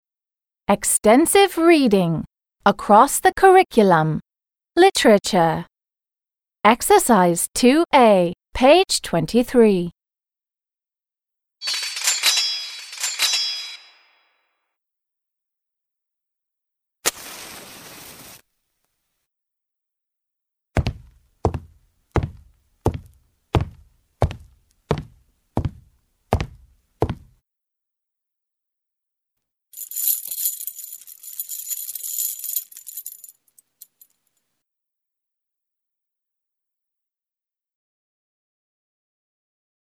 2. a) Read the title of the story and listen to the sounds. What do you think happens in this extract? − Прочитай заголовок рассказа и послушай звуки. Как ты думаешь, что происходит в этом отрывке?
Ответ: I think something scary happens in this extract. − Мне кажется, в этом отрывке происходит что-то пугающее.